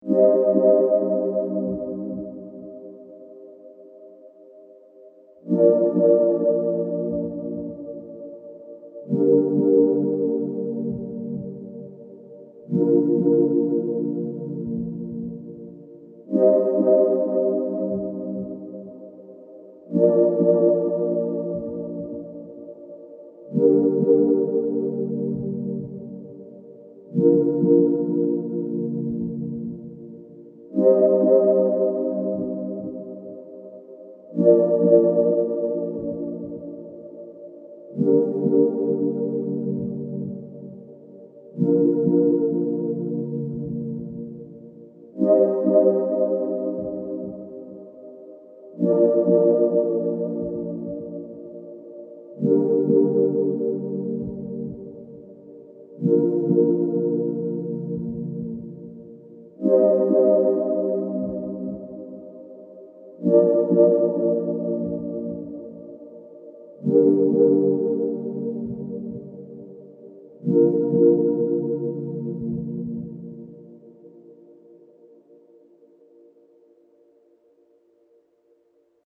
Pad